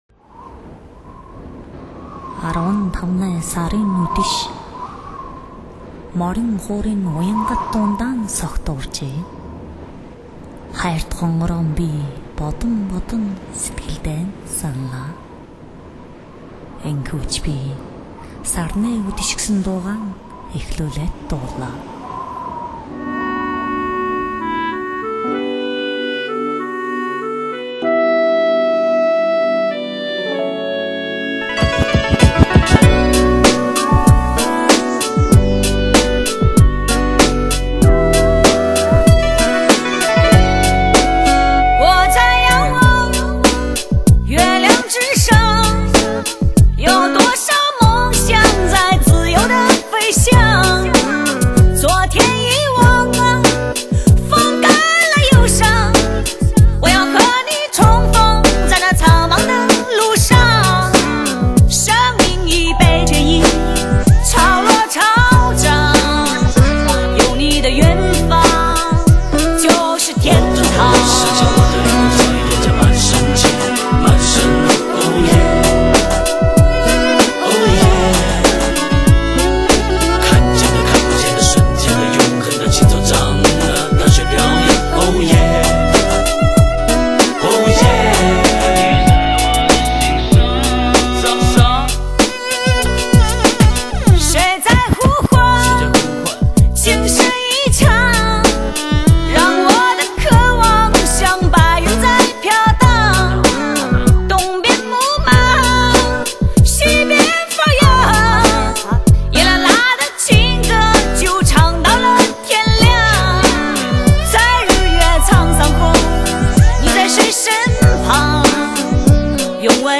高亢清亮的旋律在草原大漠 上飘扬，节奏化的说唱脱缰野马般翻滚，经典的民歌元素与电子音乐完美和谐的结合